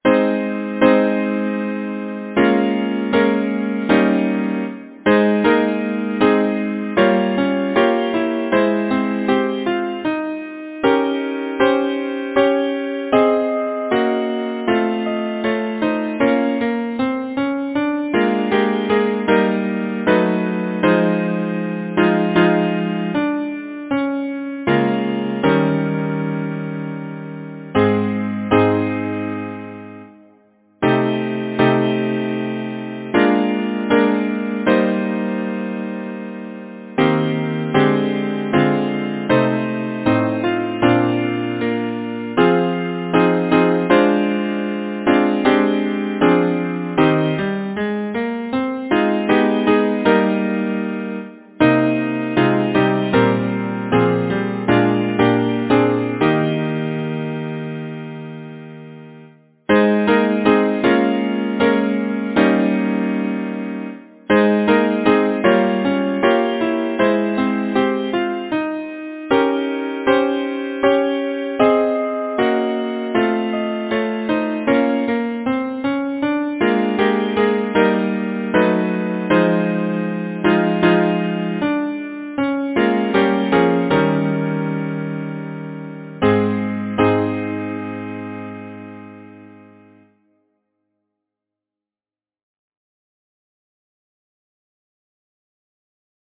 Title: Twilight Night Composer: Cecil Forsyth Lyricist: Christina Rossetti Number of voices: 4vv Voicing: SATB Genre: Secular, Partsong
Language: English Instruments: A cappella